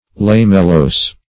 Search Result for " lamellose" : The Collaborative International Dictionary of English v.0.48: Lamellose \Lam"el*lose`\, a. [Cf. F. lamelleux.] Composed of, or having, lamellae; lamelliform.